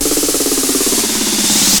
02_13_drumbreak.wav